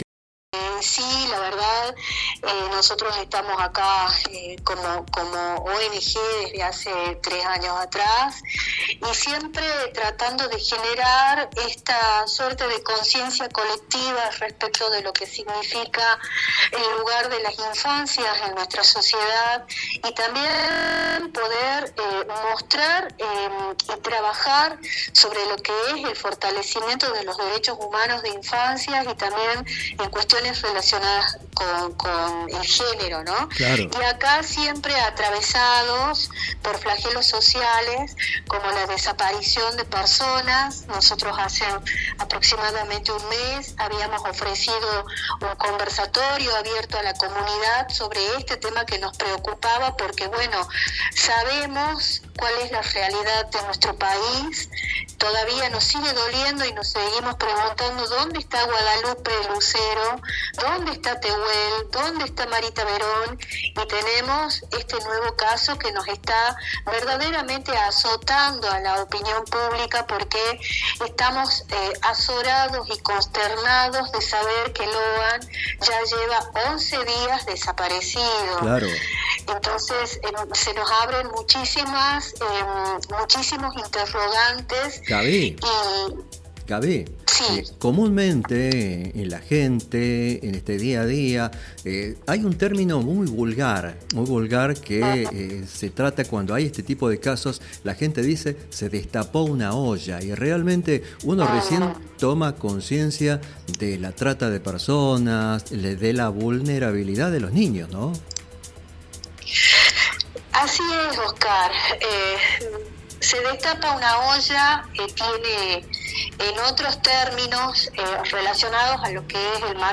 Entrevistas City